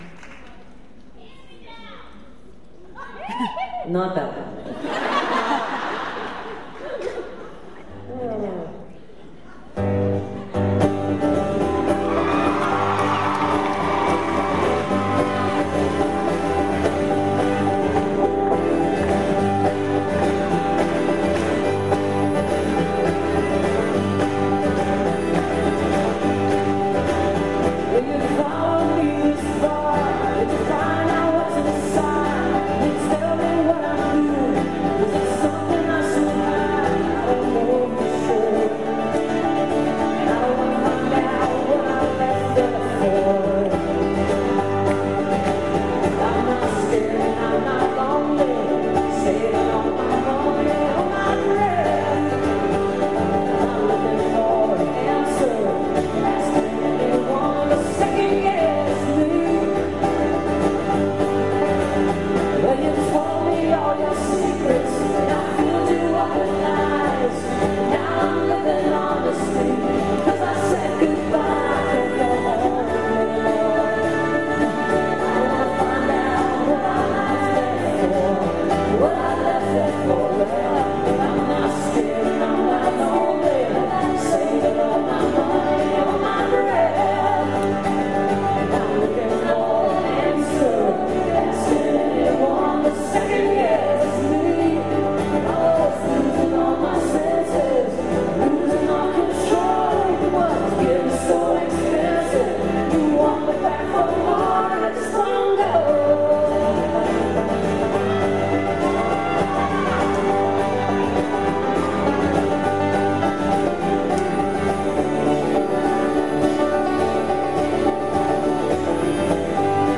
(band show)